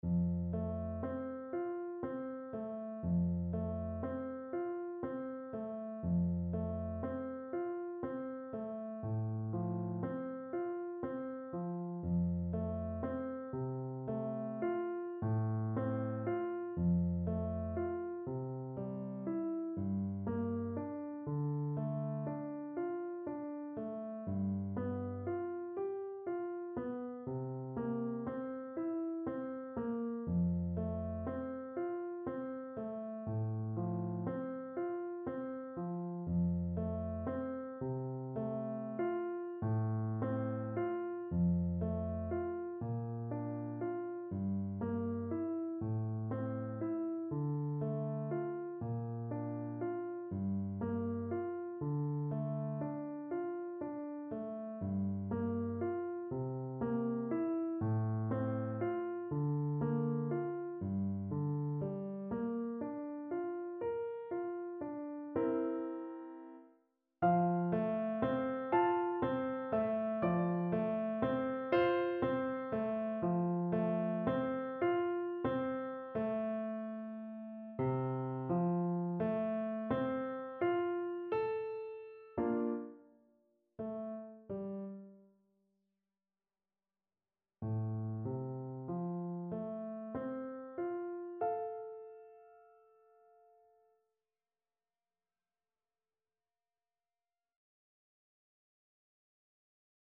Puccini: O mio babbino caro… (na klarnet i fortepian)
Symulacja akompaniamentu